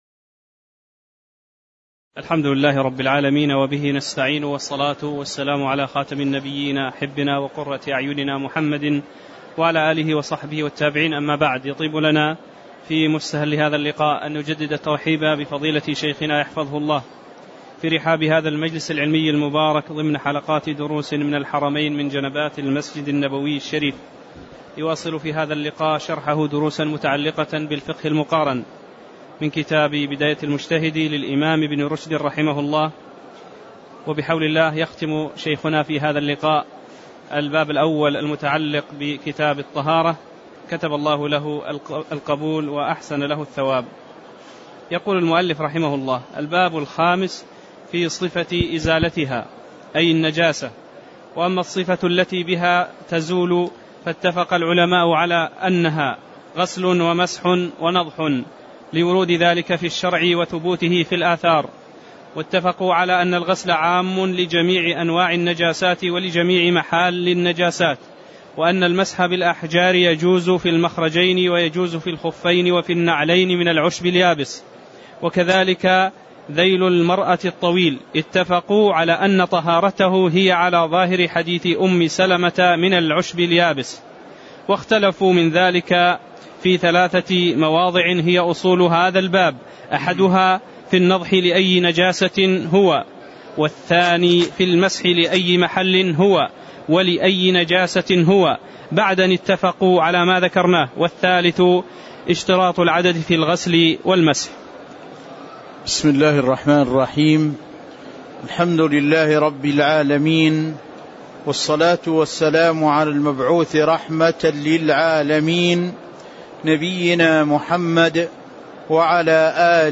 تاريخ النشر ٣ شعبان ١٤٤٠ هـ المكان: المسجد النبوي الشيخ